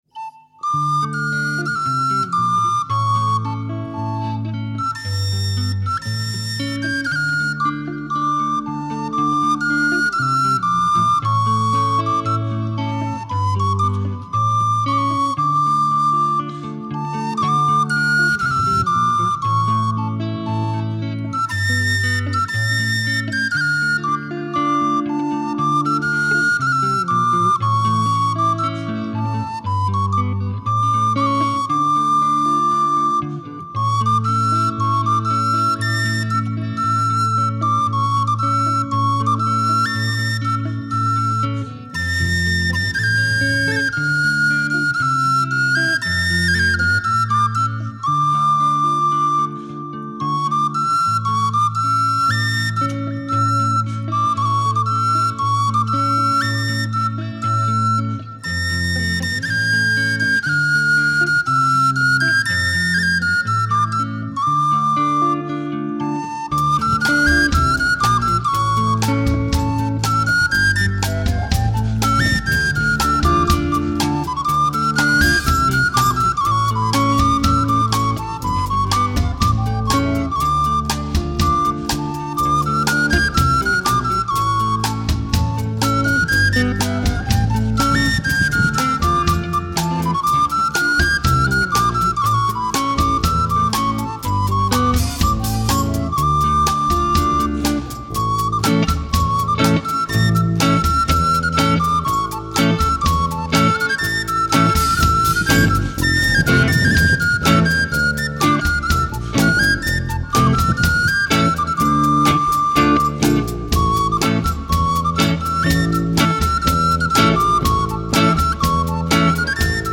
Prog flauto diritto, basso e chitarra, tamburi